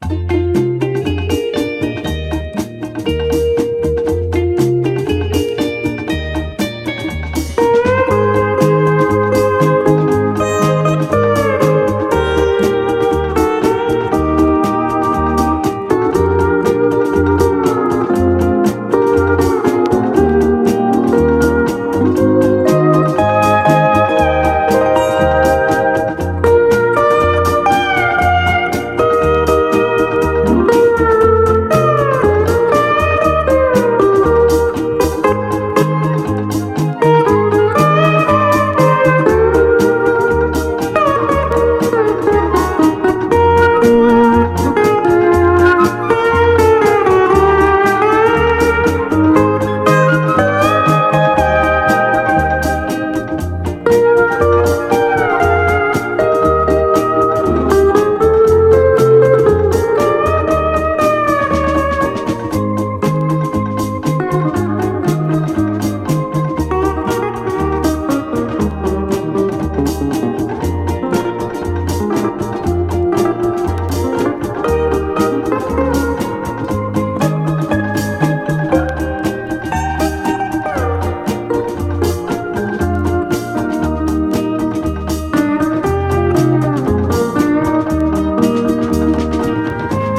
HAWAII / DRUM BREAK
後半のリズム・チェンジとラテンなパーカッションがゴキゲンな本盤のハイライト！